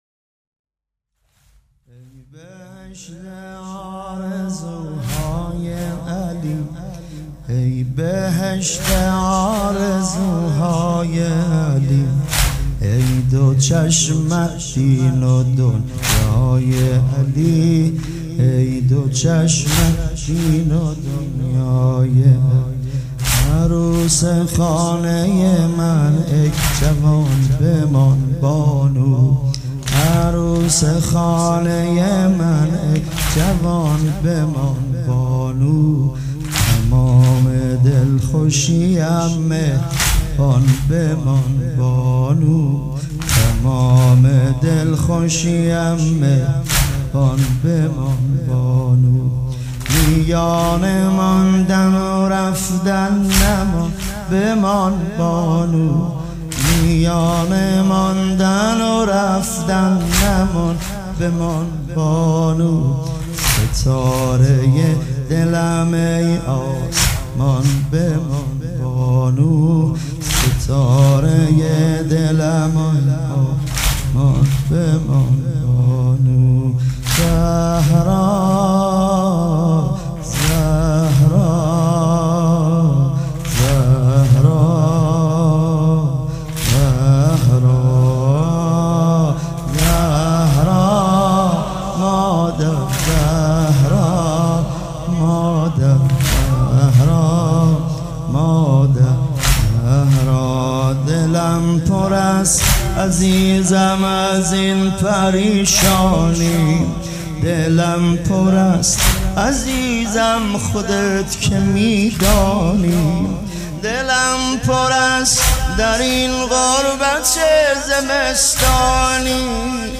مداح
مراسم عزاداری شب اول